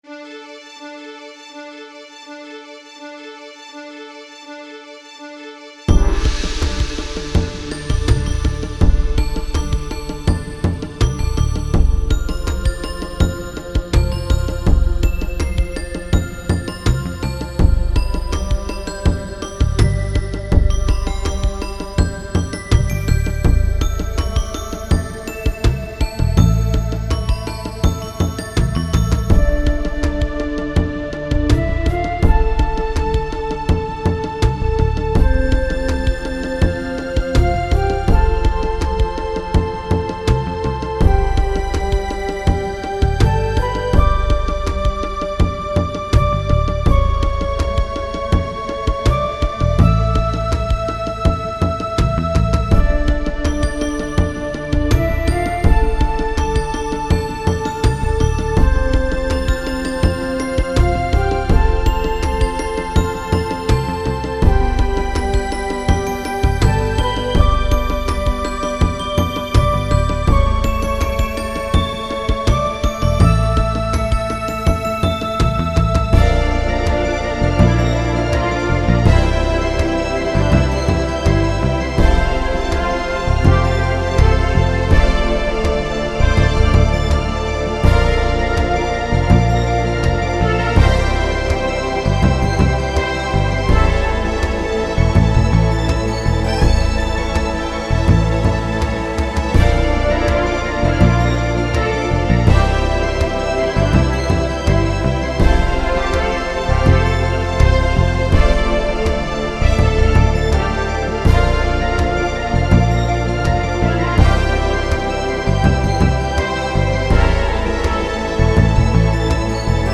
A remix of the main theme